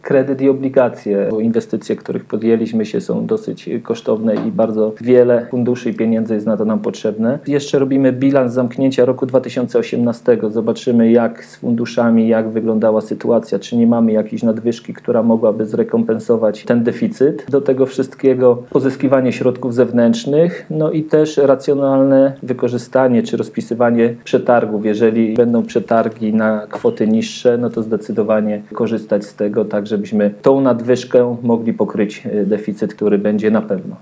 Jak mówi prezydent Mielca, Jacek Wiśniewski będziemy dalej szukać środków aby ta kwota deficytu malała, dodaje.